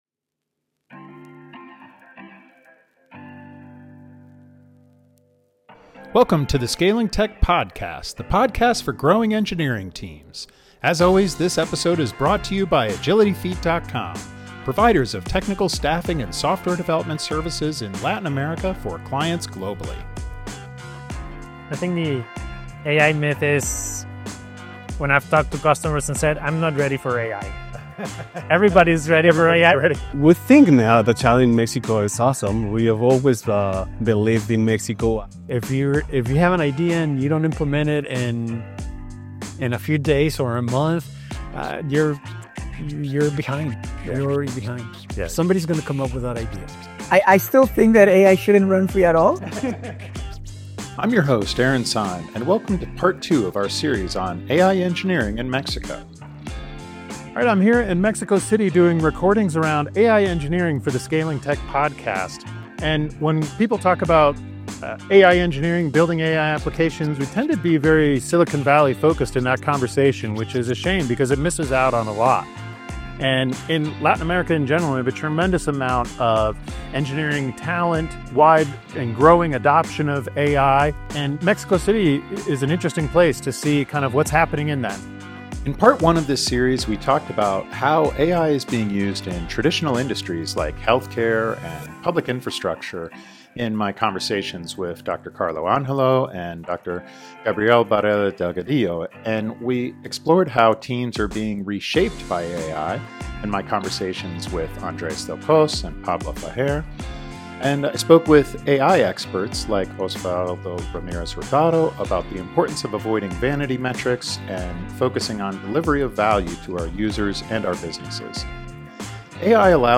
In part two of our Scaling Tech Podcast series on AI engineering in Mexico, recorded in Mexico City, learn how teams are building real AI applications in Mexico, with insights on talent, innovation, and best practices for scalable AI development.